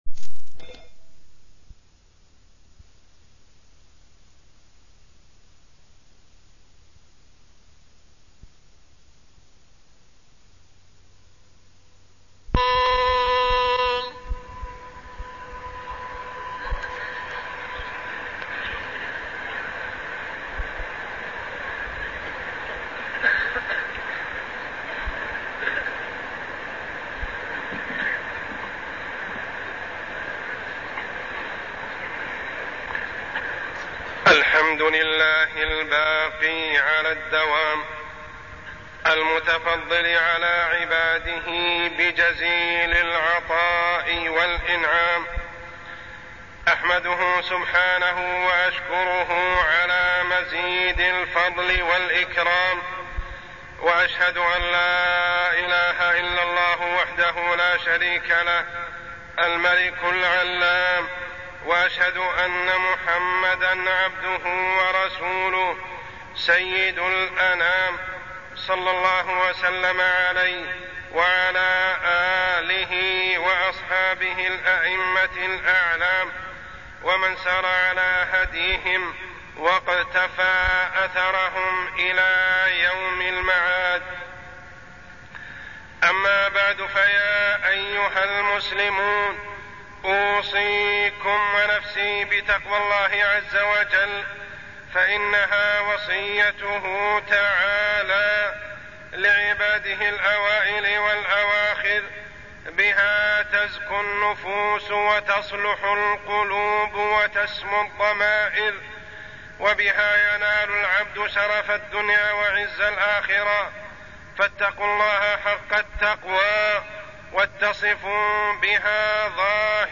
تاريخ النشر ١٨ ذو الحجة ١٤٢٠ هـ المكان: المسجد الحرام الشيخ: عمر السبيل عمر السبيل الإقبال على الله ودوام العبادة